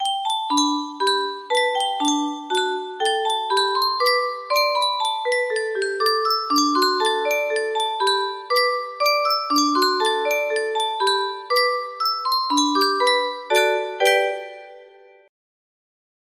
Clone of Yunsheng Spieluhr - An der Saale Hellem Strande 2269 music box melody